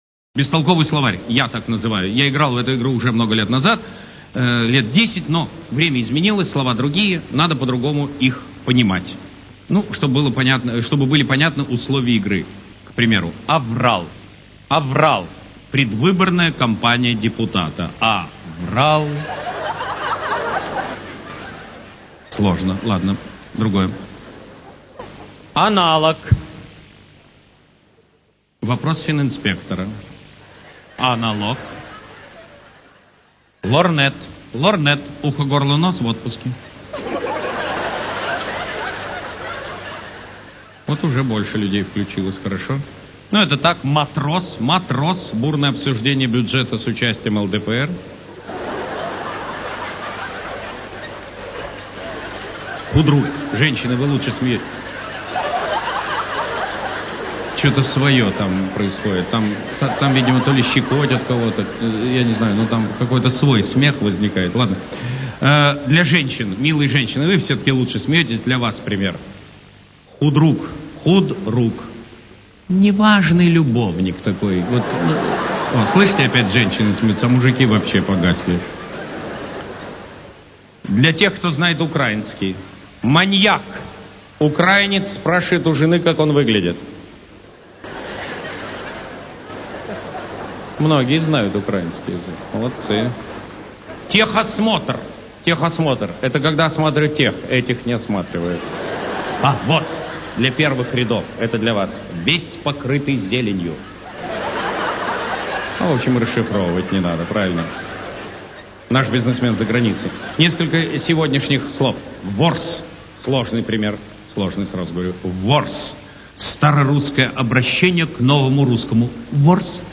Юмор. Полный.